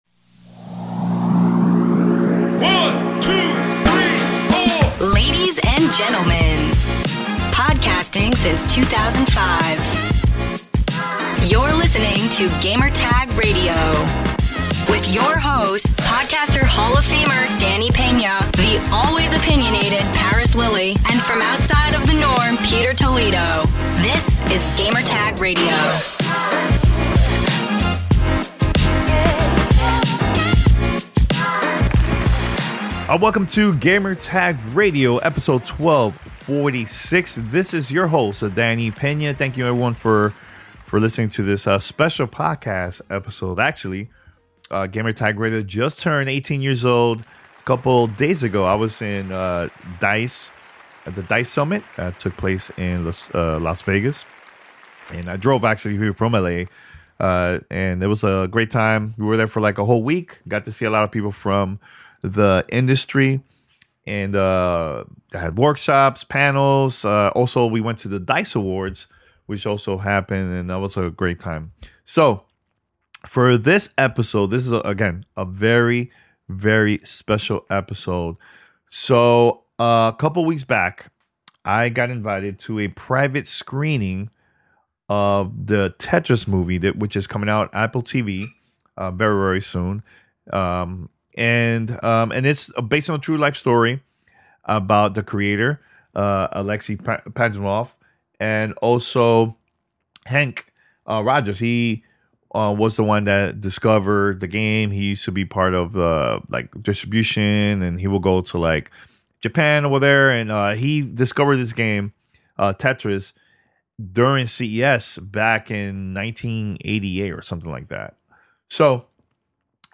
Tetris Movie Interview with Alexey Pajitnov and Henk Rogers